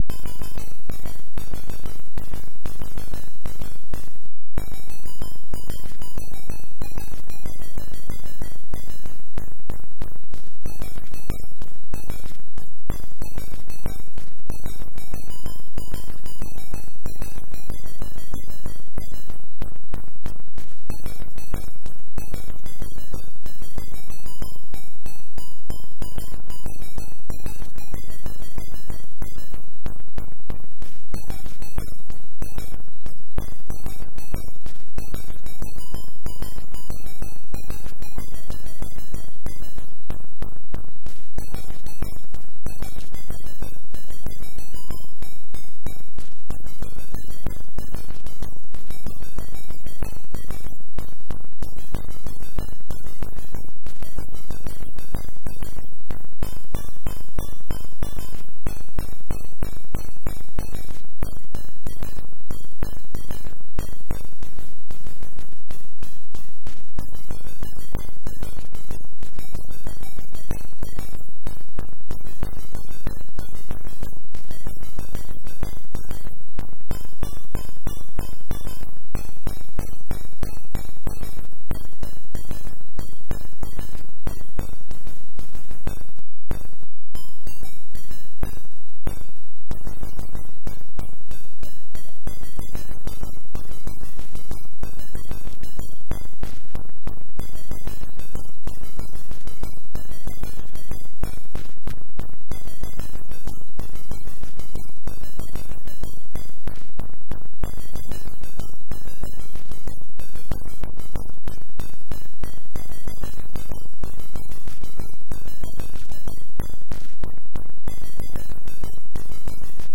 "Peaches and Cream" by Percy Wenrich, 1905. My original arrangement for Apple II + Mockingboard. Recorded in MAME 0.254.